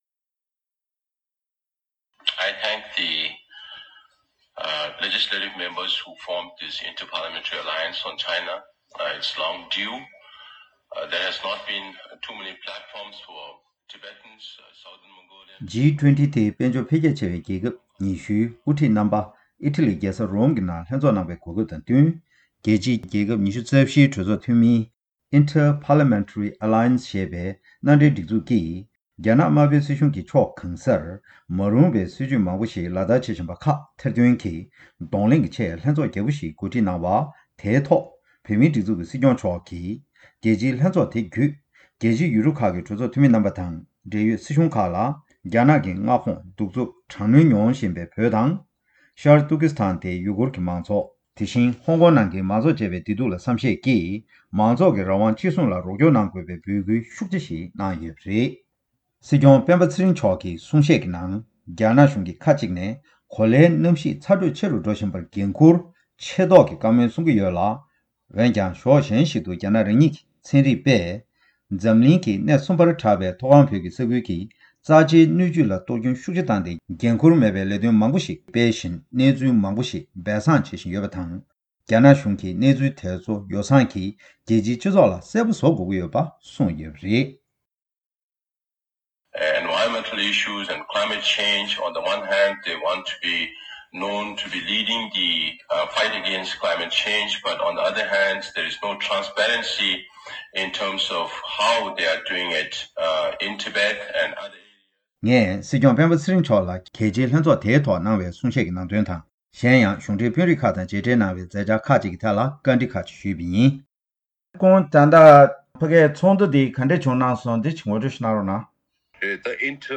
སྲིད་སྐྱོང་མཆོག་གིས་རྒྱལ་སྤྱིའི་ལྷན་འཛོམས་ལ་གནང་བའི་གསུང་བཤད་ཀྱི་སྐོར་ལ་བཅའ་འདྲི་ཞུས་པ།